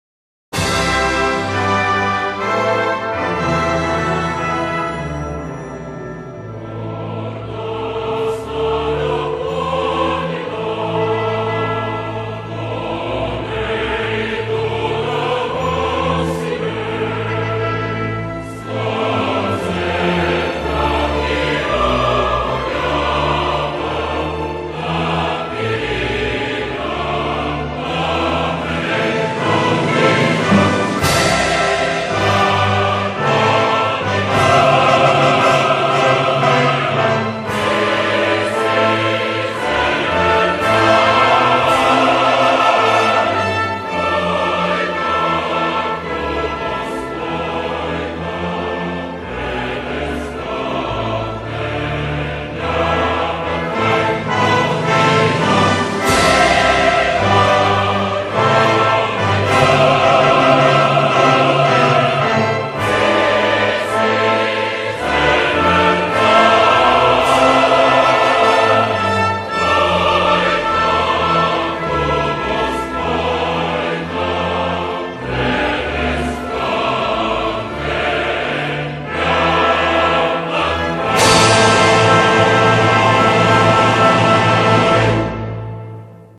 Bulgarian_anthem.mp3